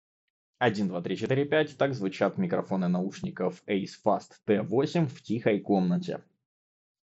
Микрофон
Заявленное активное шумоподавление, которое должно работать при телефонных разговорах, мало того, что работает и при записи с диктофона, так еще и неплохо срезает шум.
Я же скажу — звук не эталонный, но для телефонных разговоров наушники подходят целиком и полностью.
В тихих условиях: